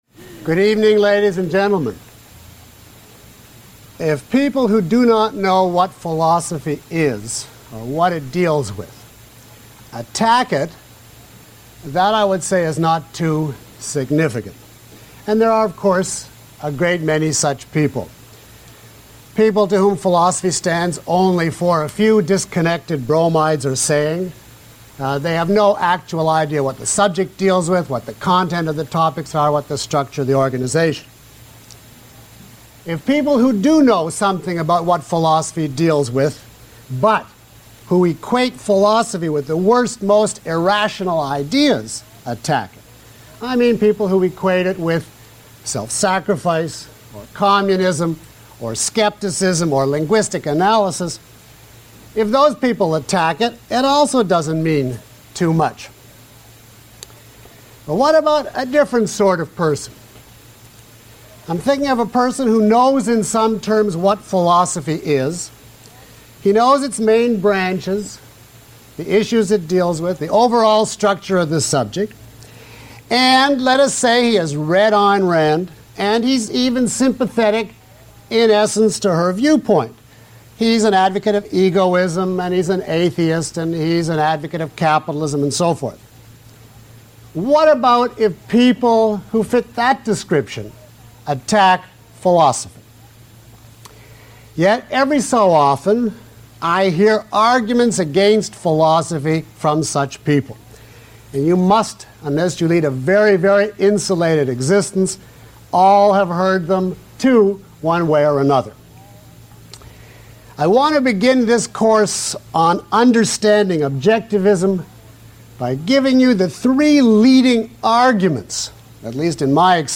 This course features lecture material by Dr. Peikoff as well as exercises and demonstrations from the live audience.